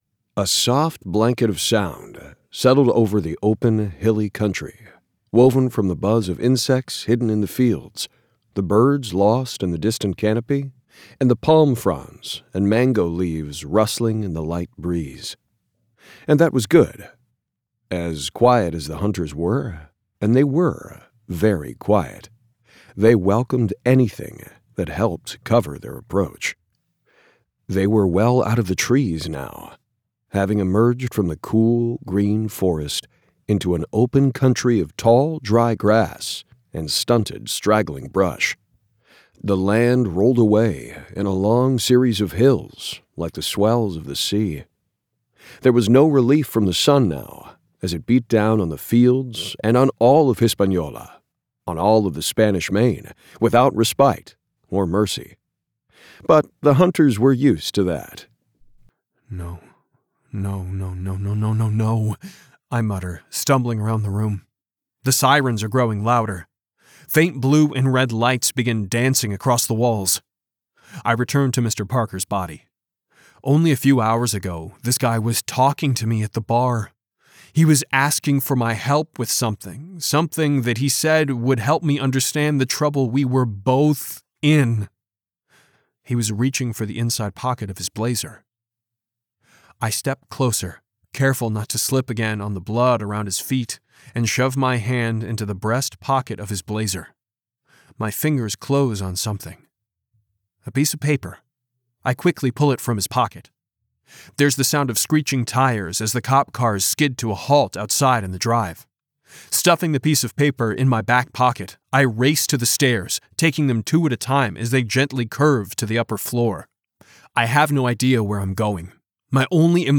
Audiobook Showreel
Male
Warm